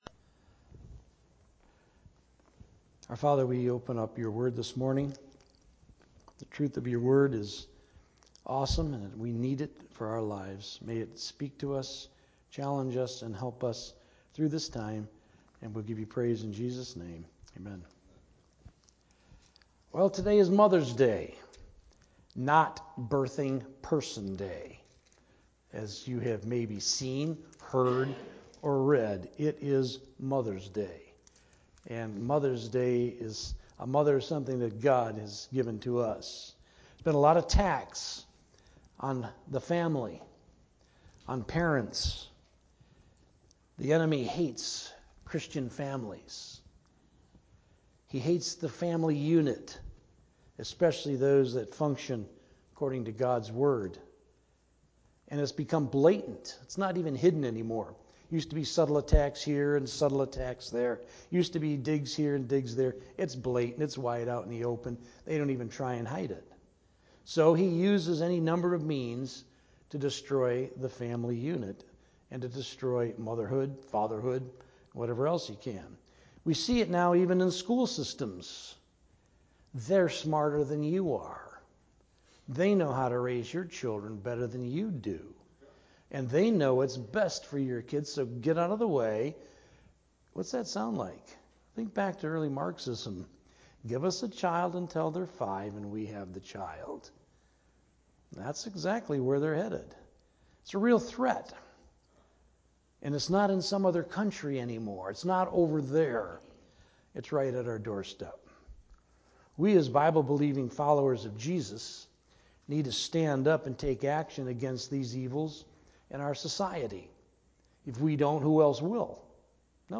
Sermon Audio | FCCNB